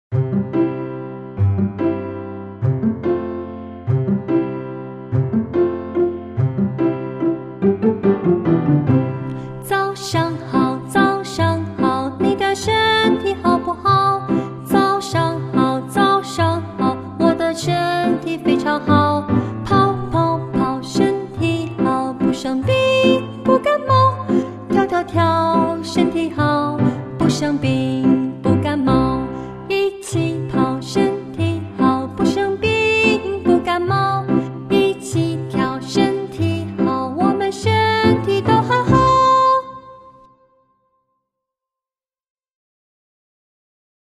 Cùng hát nhé
Bây giờ, chúng ta cùng ôn lại bài thông qua bài hát vui nhộn dưới đây nhé!